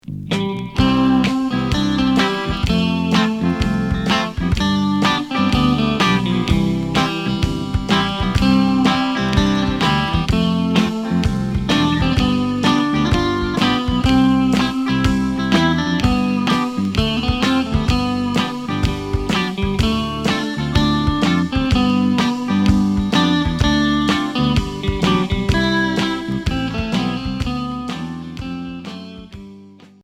Rock instrumental Unique 45t retour à l'accueil